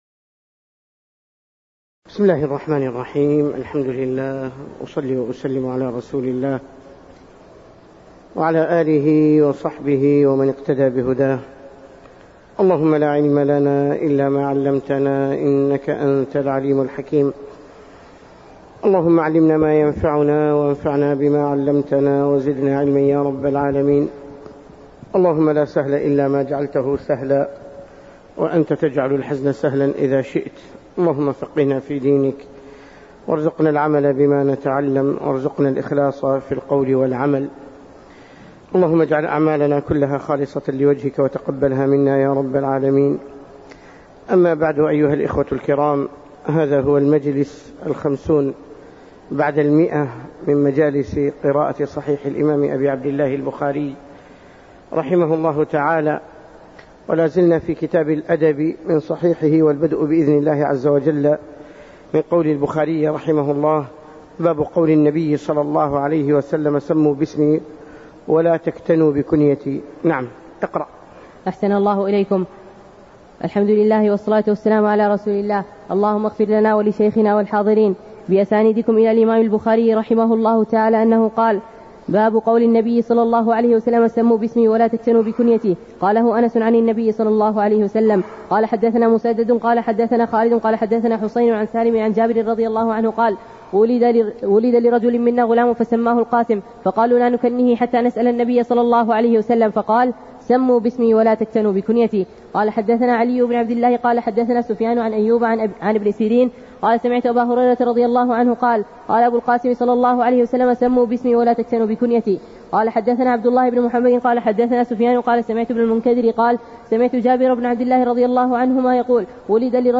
تاريخ النشر ٢٠ محرم ١٤٣٩ هـ المكان: المسجد النبوي الشيخ